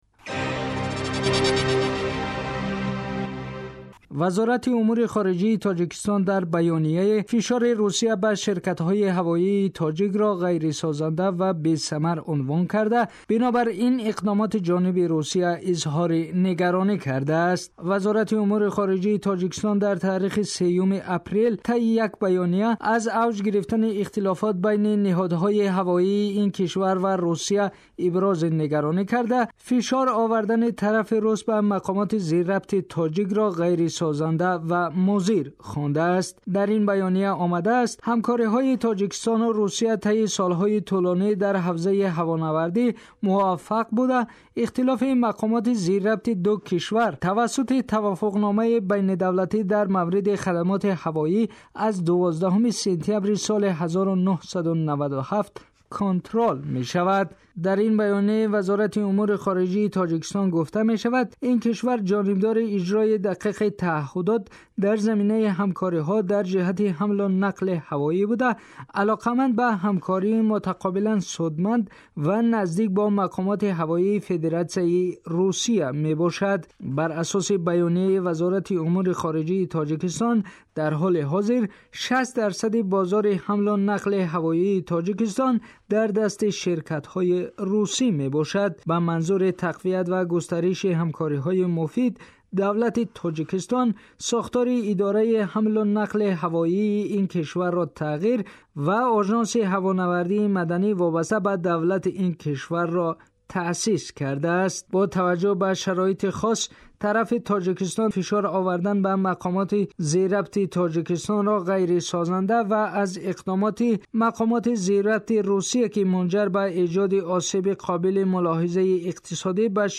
گزارش ویژه ؛ فشار غیرسازنده روسیه بر تاجیکستان در ماجرای پروازها